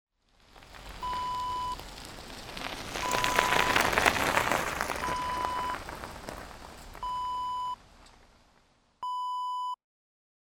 Bicycle Pass By Wav Sound Effect
Description: The sound of a bicycle passing by on a gravel road
Properties: 48.000 kHz 24-bit Stereo
A beep sound is embedded in the audio preview file but it is not present in the high resolution downloadable wav file.
bicycle-pass-by-preview-1.mp3